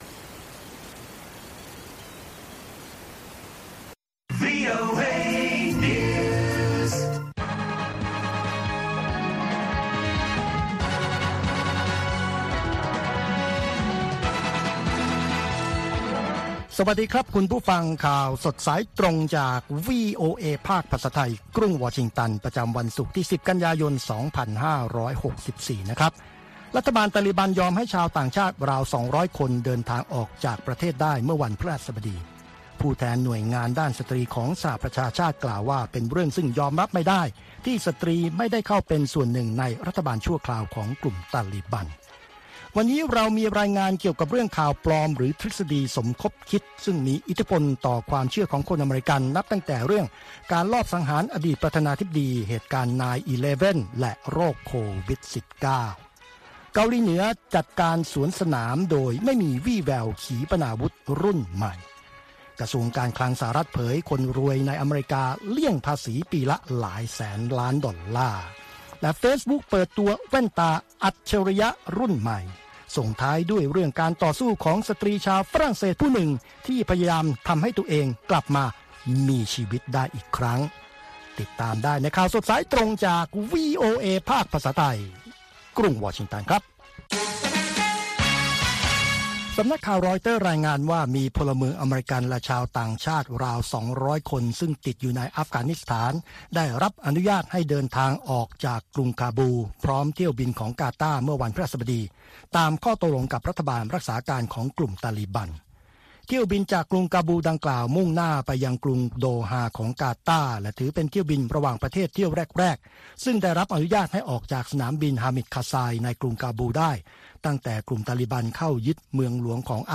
ข่าวสดสายตรงจากวีโอเอ ภาคภาษาไทย ประจำวันศุกร์ที่ 10 กันยายน 2564 ตามเวลาประเทศไทย